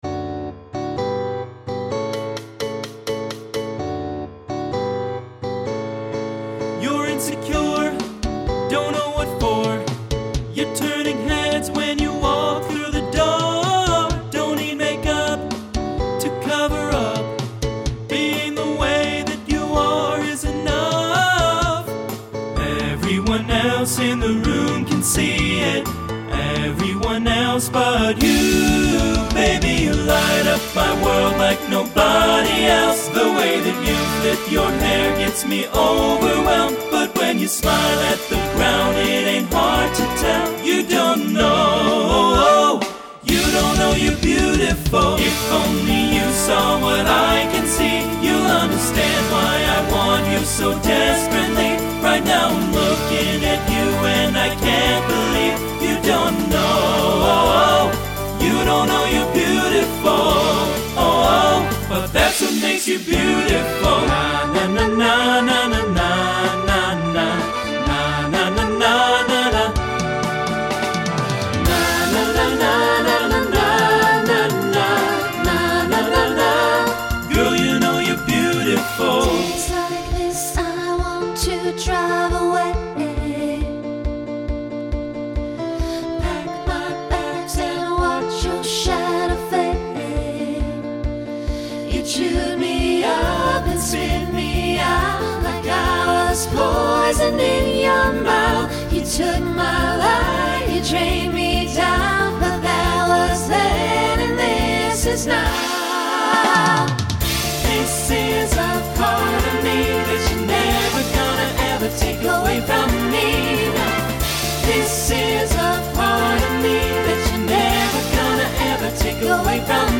Voicing Mixed Instrumental combo Genre Pop/Dance